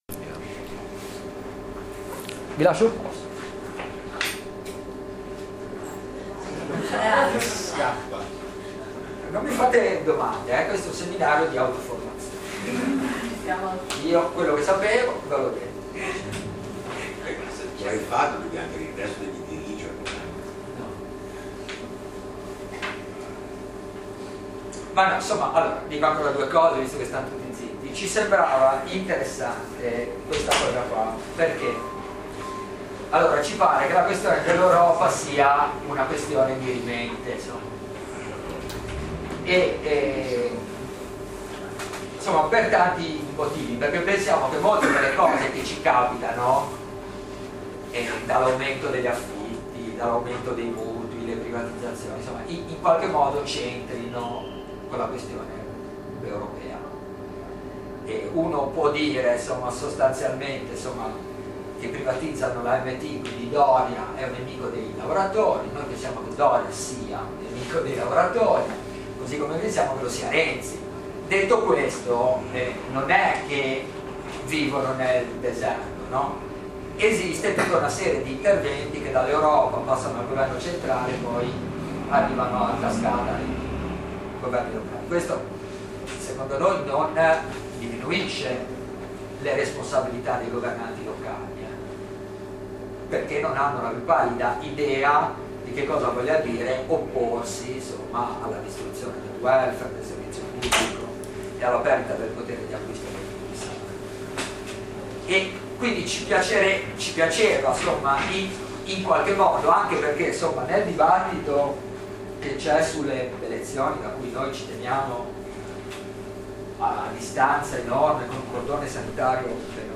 Versione pdf Quello che segue è il testo del seminario sulla storia del progetto di integrazione europea che si è tenuto il 4 marzo 2014 presso la libreria del popolo al piano terra della Casa Occupata di Pellicceria a Genova.